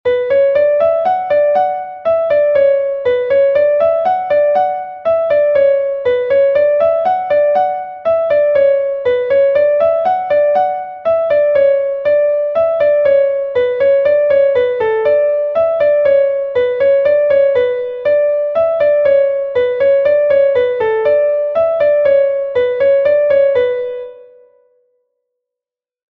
Ridée Pevar Den III est un Laridé de Bretagne enregistré 1 fois par Pevar Den